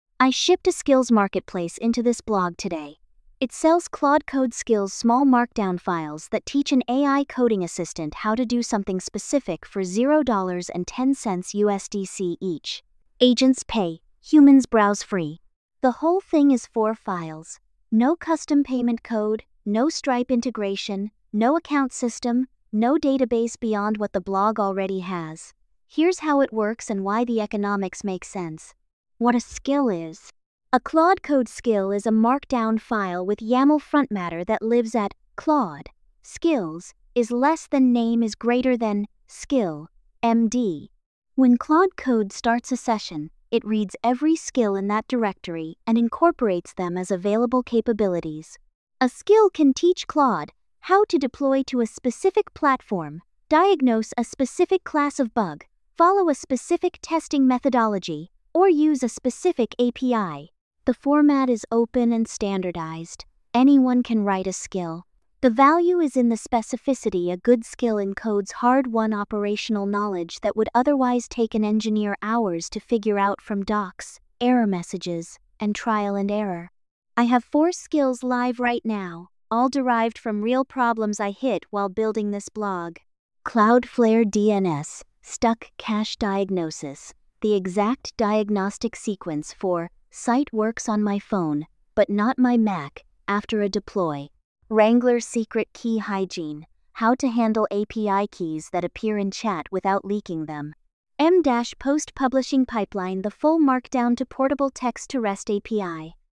AI-narrated with MiniMax speech-2.8-hd · 1:46. Hit play or use the controls.